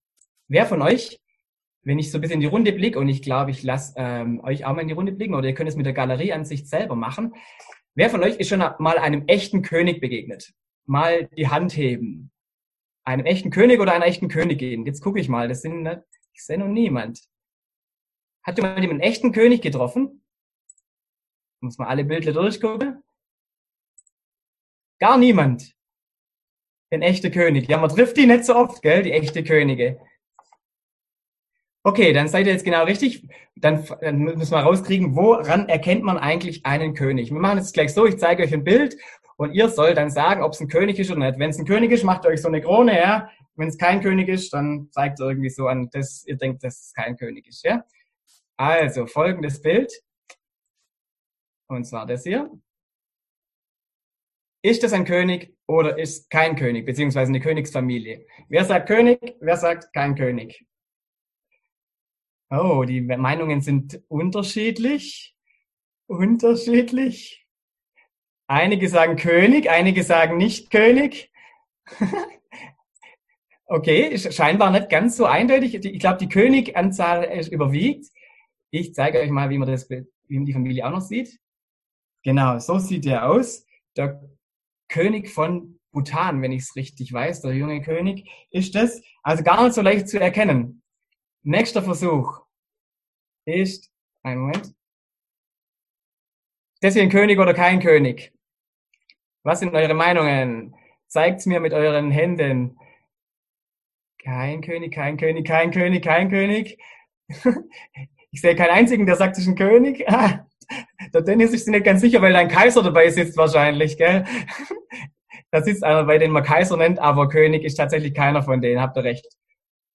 Predigt
im Online-Gottesdienst am Palmsonntag